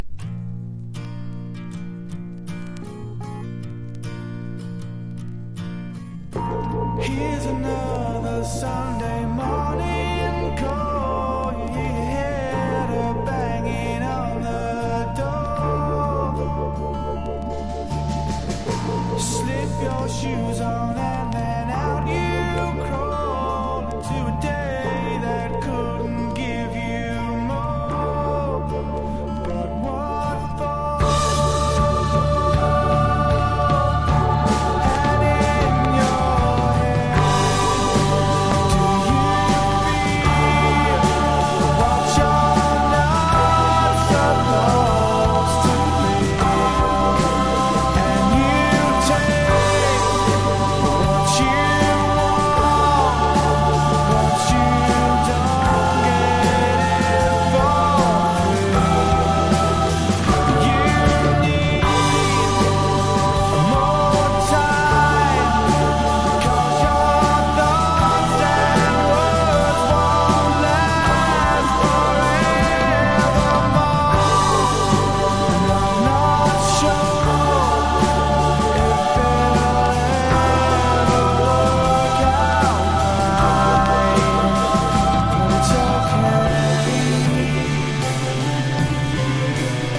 優しいメロディーのバラード・ナンバー